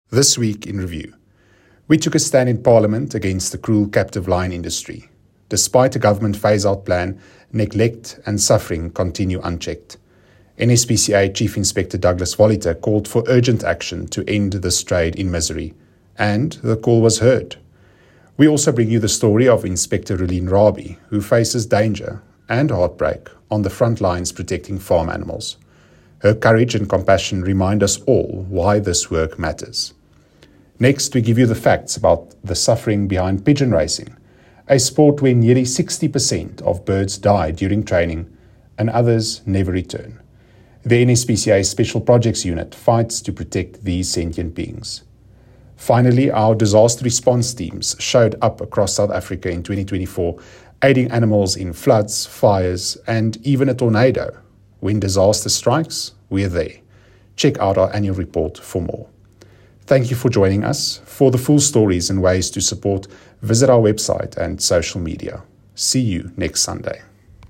Voice-Over.mp3